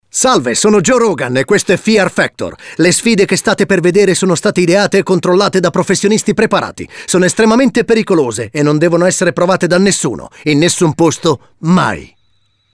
attore doppiatore